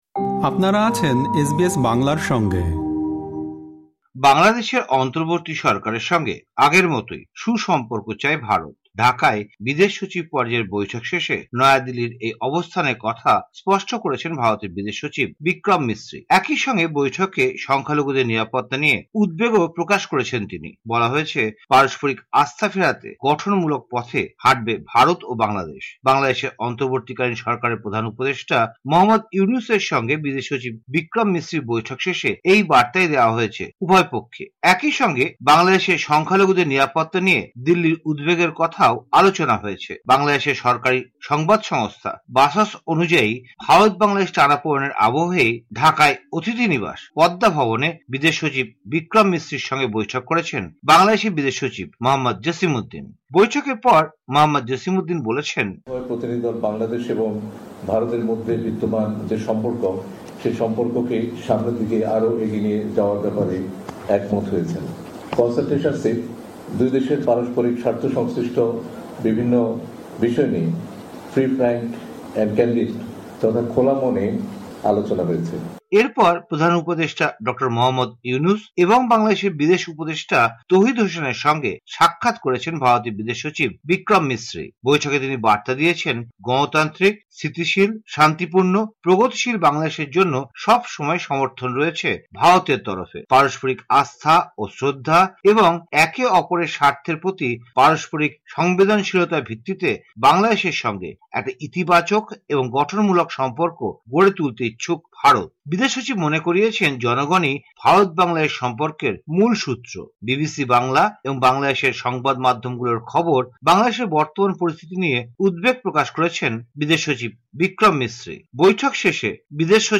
সম্পূর্ণ প্রতিবেদনটি শুনতে উপরের অডিও প্লেয়ারটিতে ক্লিক করুন।